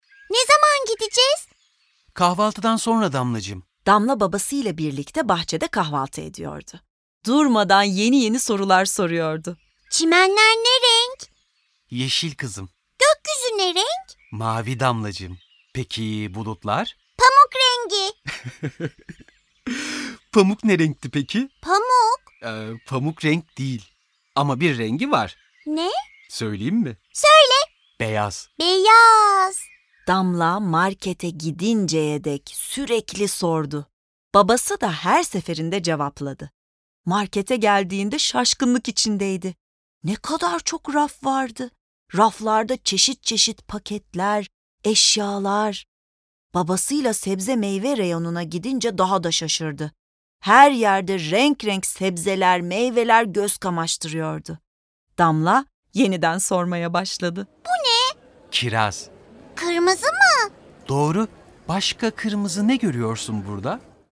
Bu Sesli Tiyatro eseri, okumalı boyama kitaplarıyla desteklenmektedir.
Ses Tasarım ve Mix: Sound Atlas